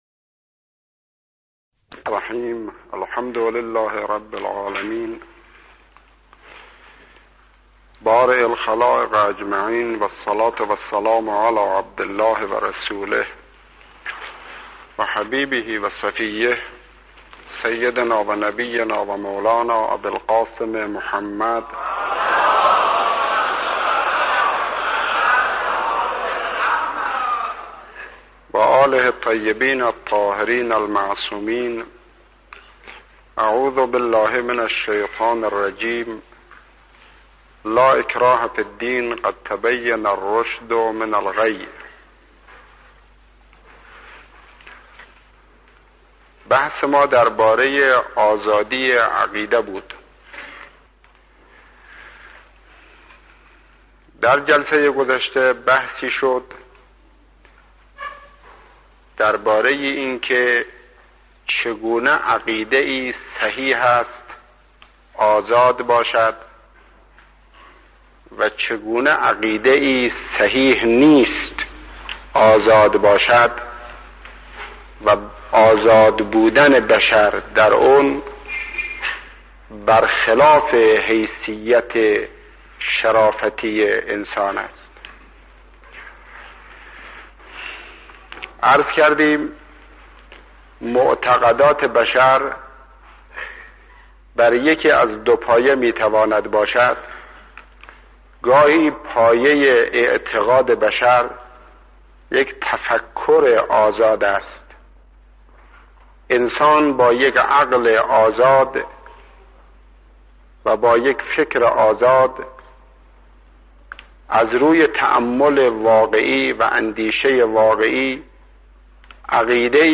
سخنرانی های آیت الله شهید مطهری بخش چهارم